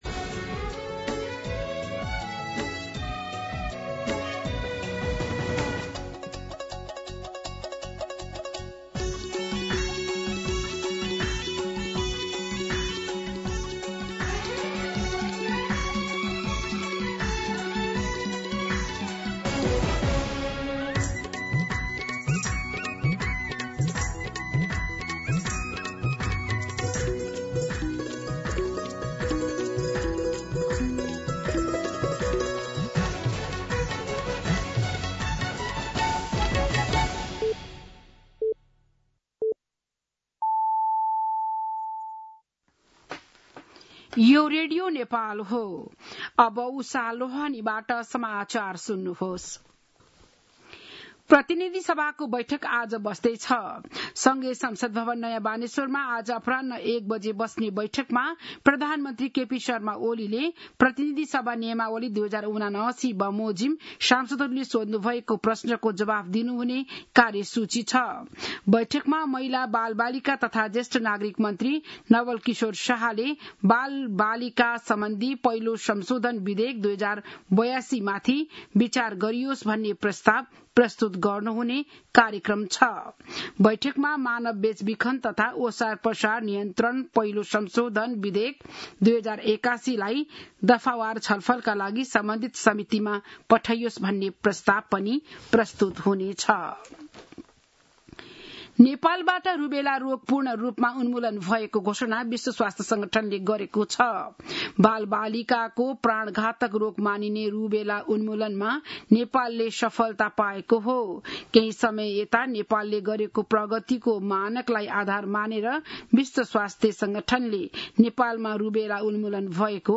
बिहान ११ बजेको नेपाली समाचार : ४ भदौ , २०८२
11am-News-05-4.mp3